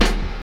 Snare (Barry Bonds).wav